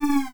Hit1.wav